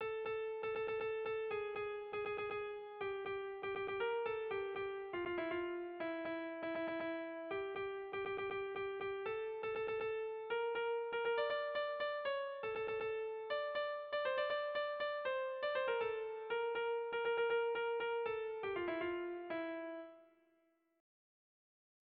Tragikoa
A-B-C-D-E